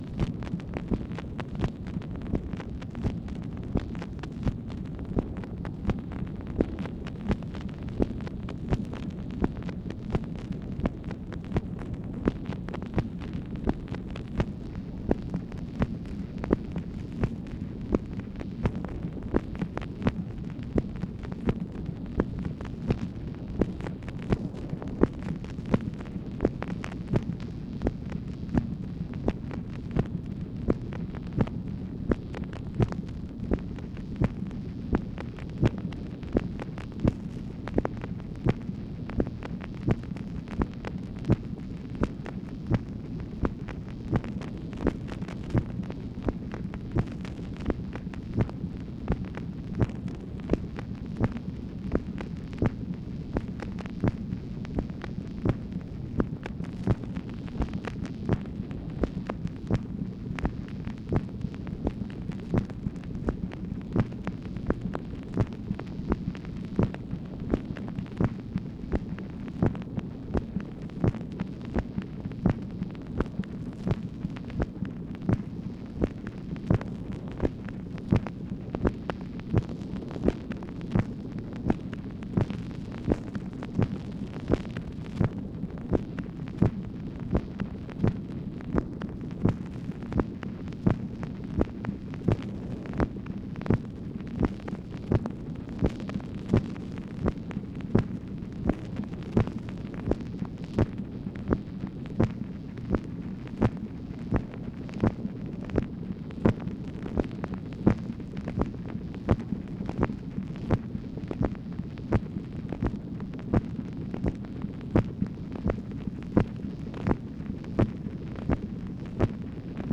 MACHINE NOISE, June 10, 1965
Secret White House Tapes | Lyndon B. Johnson Presidency